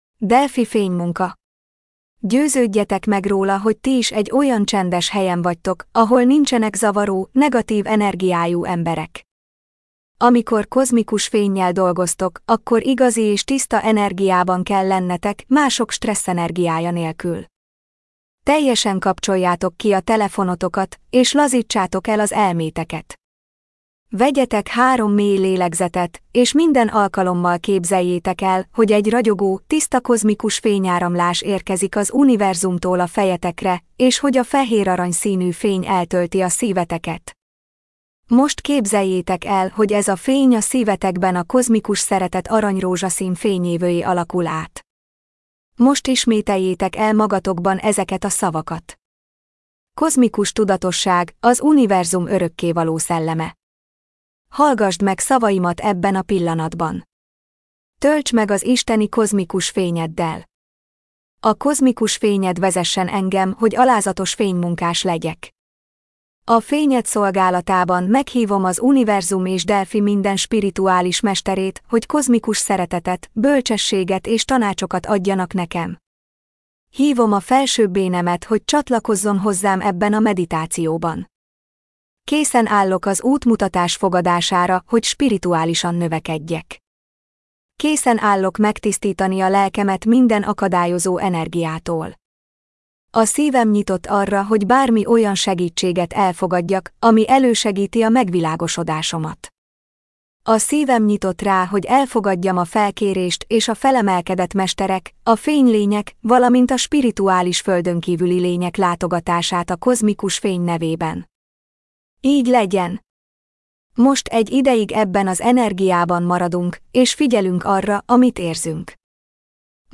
Magyar nyelvű gépi felolvasás